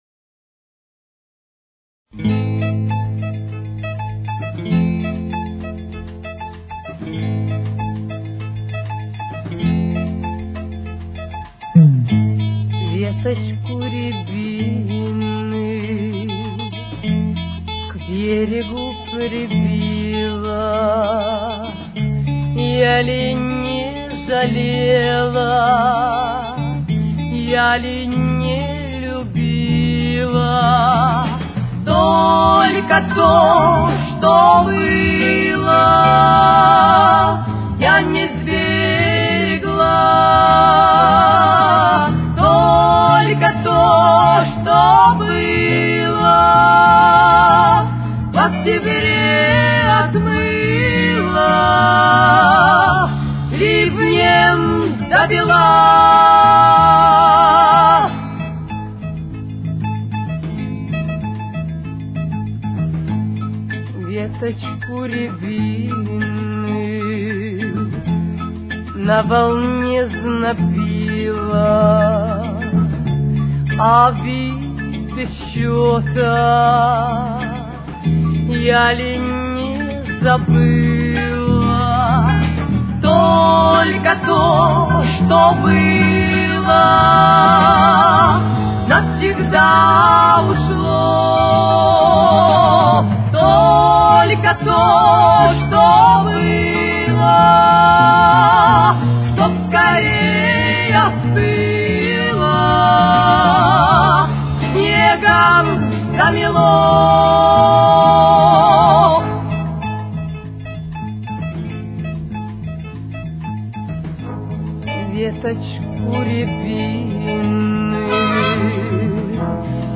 с очень низким качеством (16 – 32 кБит/с)
Тональность: Ля минор. Темп: 98.